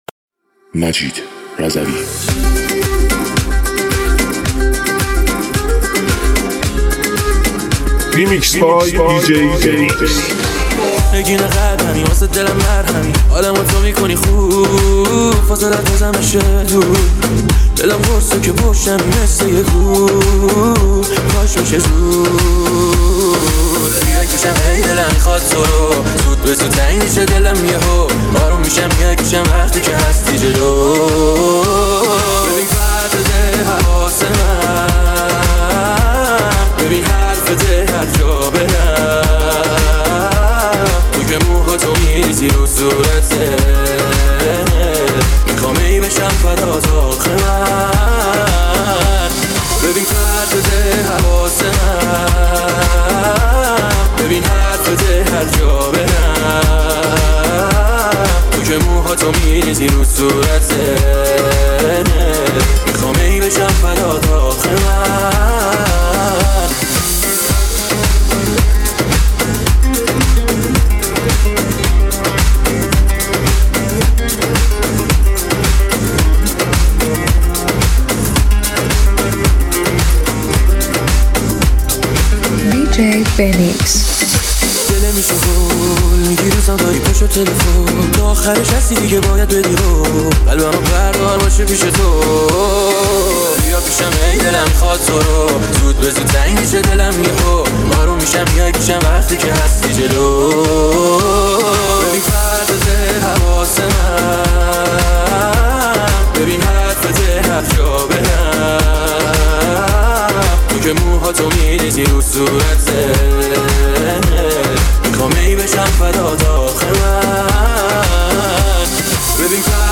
ریمیکس شاد و پر انرژی
با ریتمی تند و پرانرژی